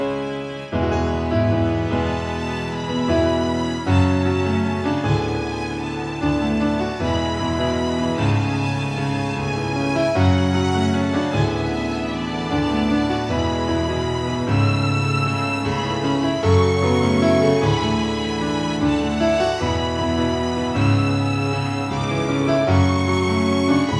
No Backing Vocals Rock 4:24 Buy £1.50